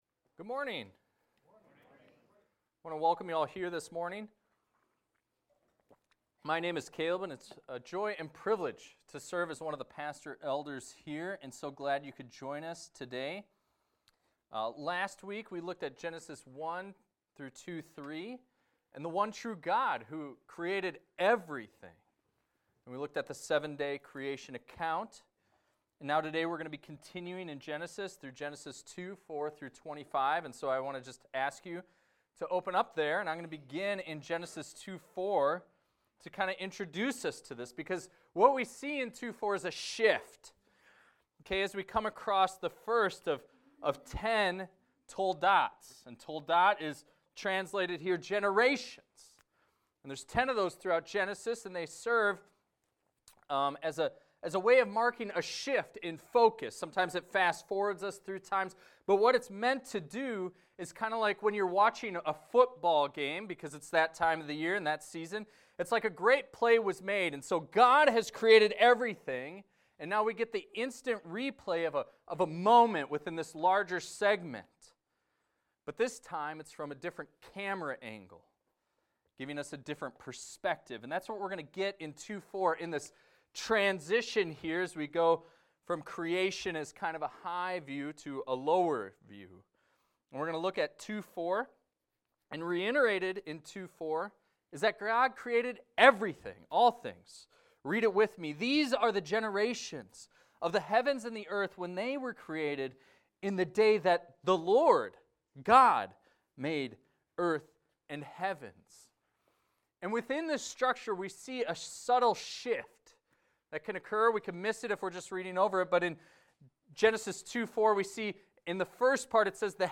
This is a recording of a sermon titled, "In the Garden."